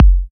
kits/OZ/Kicks/K_Keys.wav at main